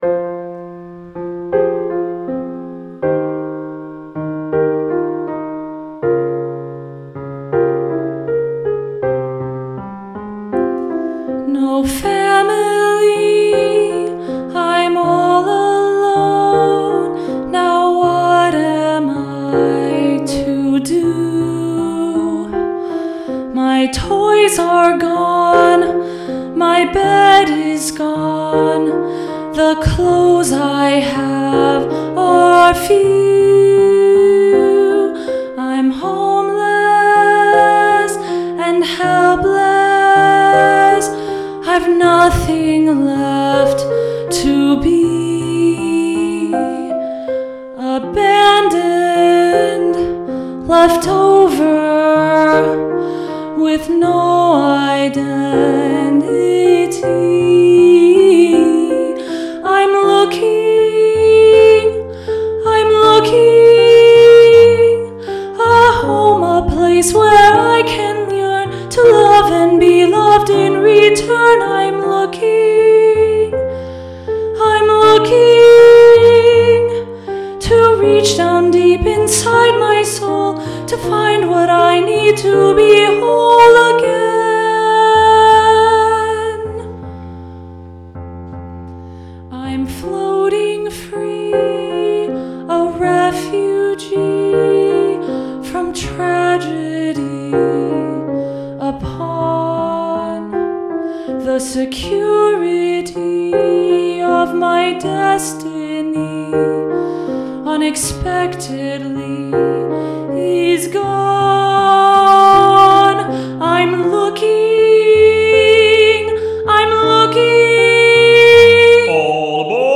The primary vibe is the golden age of Broadway, but there’s some gospel, there’s jazz, there’s funk; there’s even a bit of boy-band/BTS snuck in here and there. Here are some rough demos of a few of the songs: